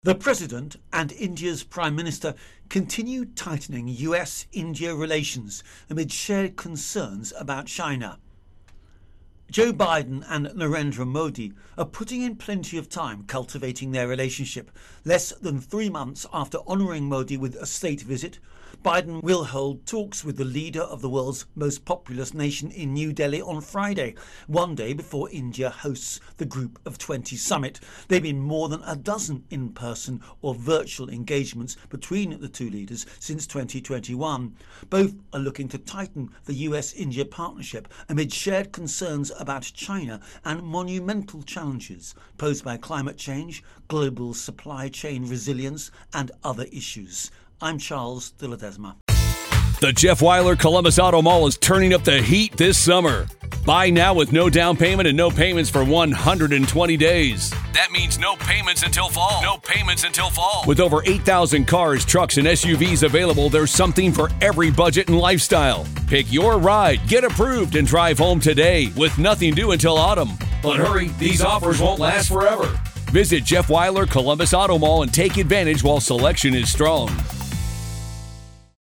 reports on G20 Biden Modi.